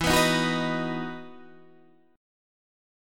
E Major 7th Suspended 2nd